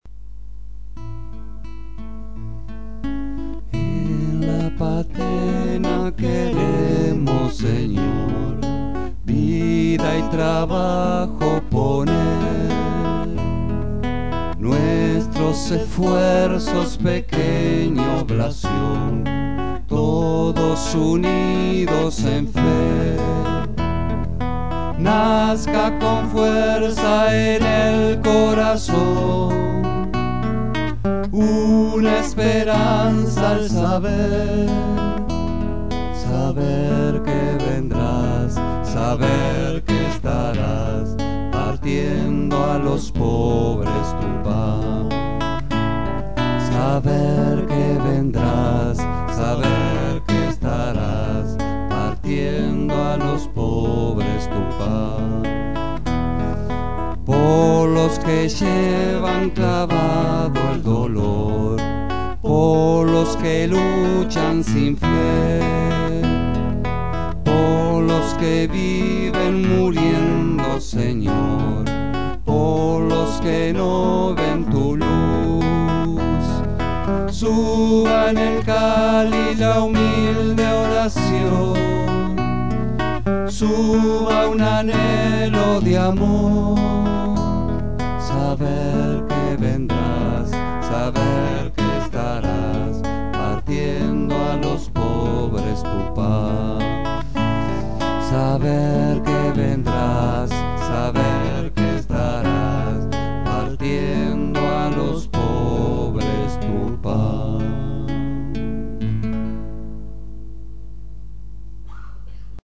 voz y guitarra